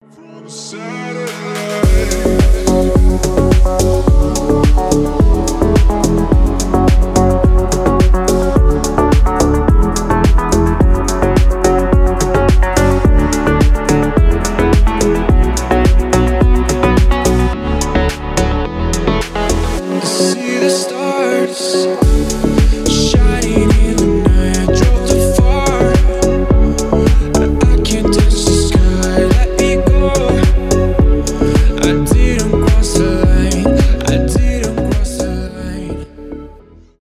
deep house , атмосферные , космические
electronic